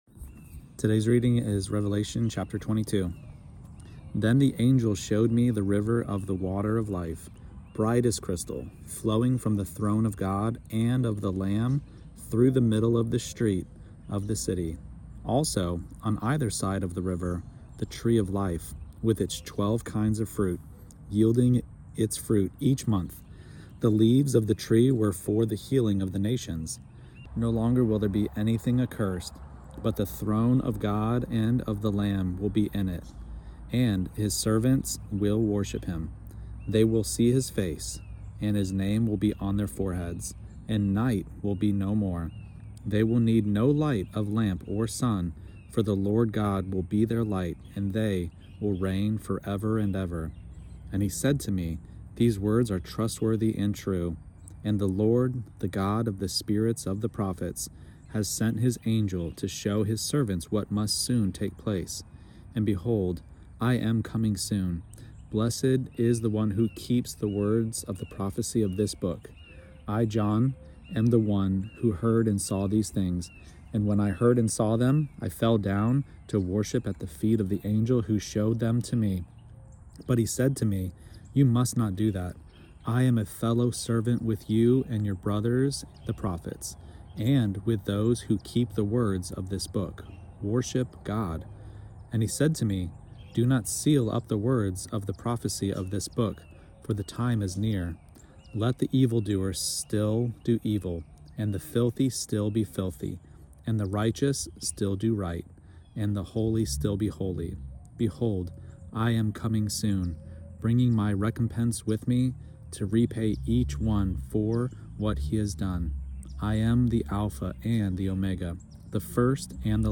Daily Bible Reading